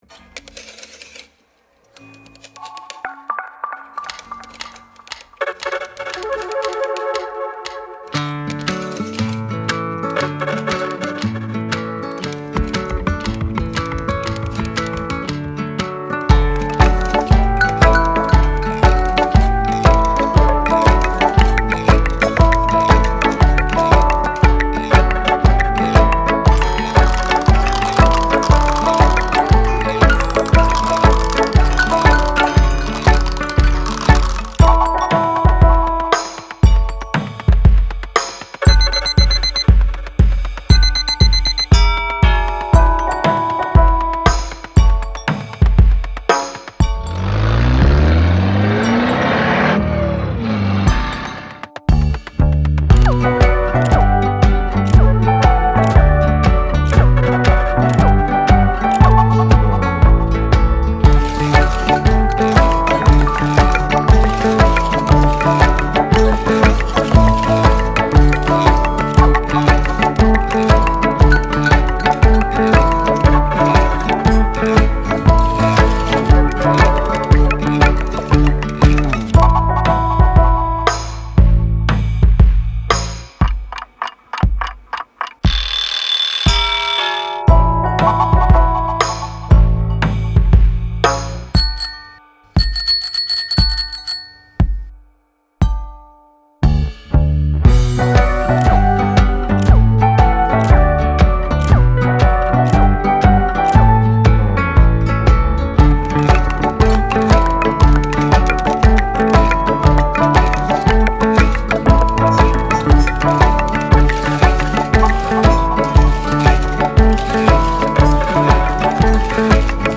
Version instrumentale avec guide-chant